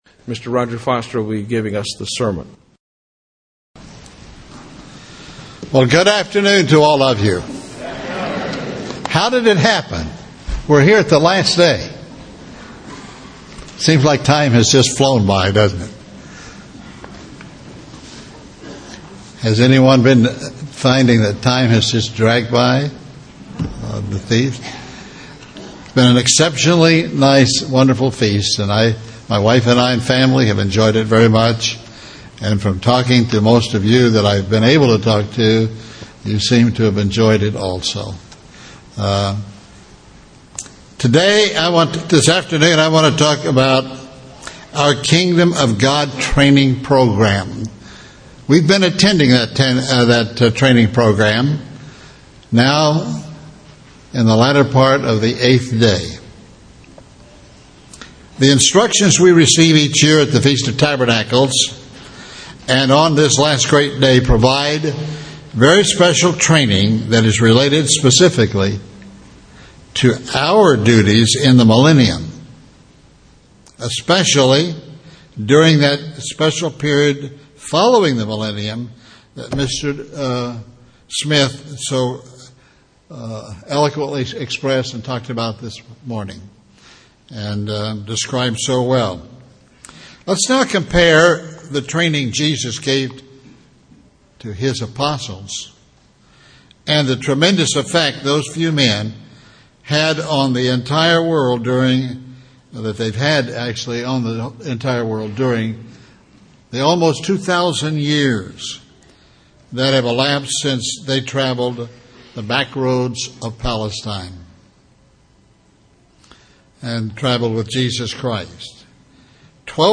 This sermon was given at the Galveston, Texas 2011 Feast site.